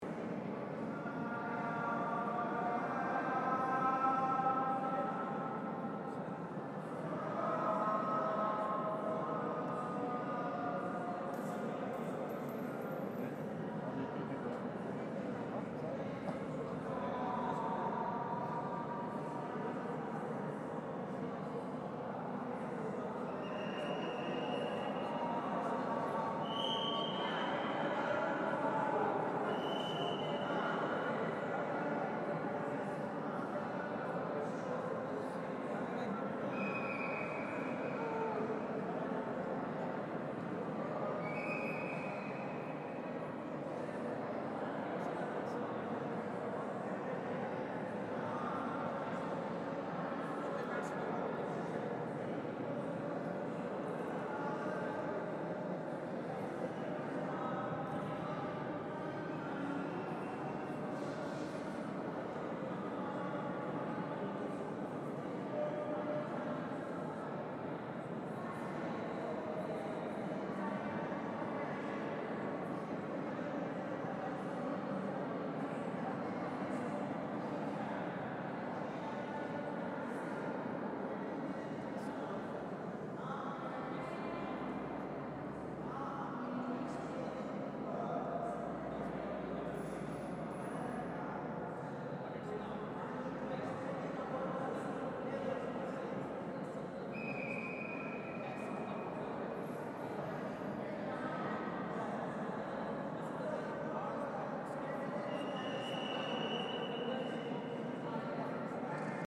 Tourists in the Taj Mahal interior. Stereo 48kHz 24bit.